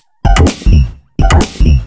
Index of /VEE/VEE2 Loops 128BPM
VEE2 Electro Loop 143.wav